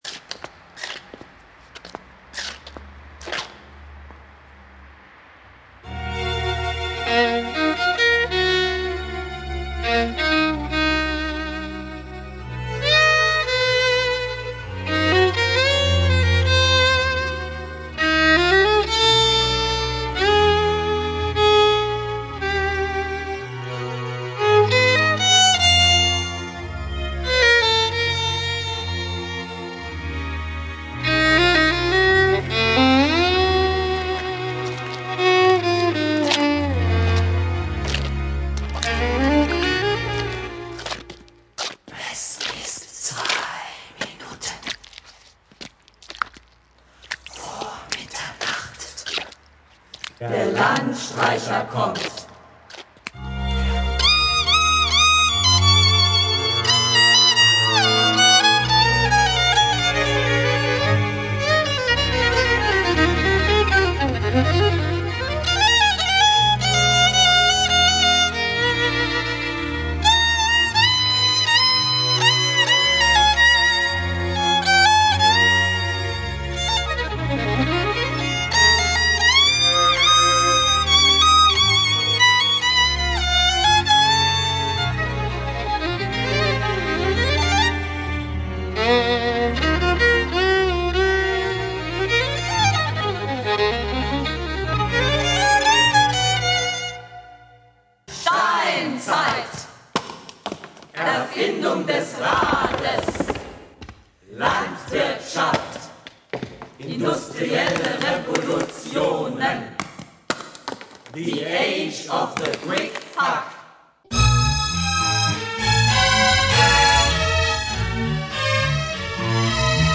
monologue